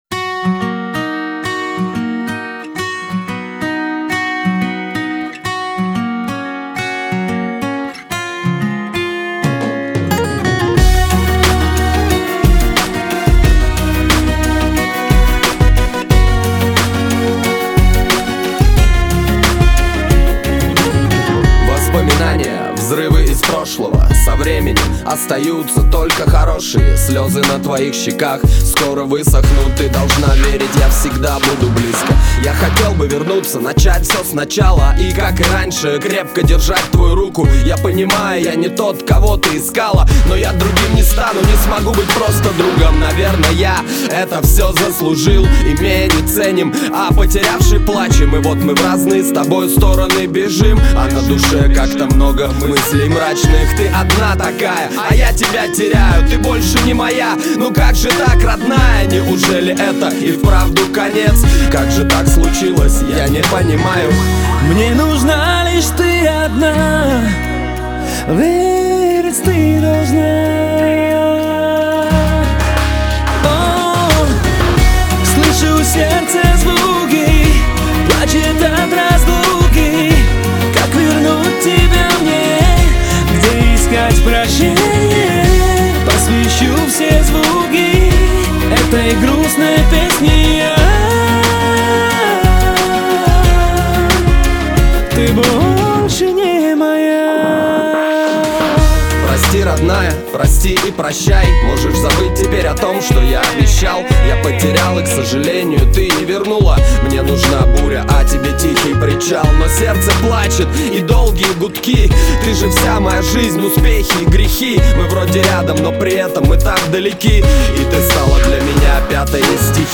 Категория: Русский реп, хип-хоп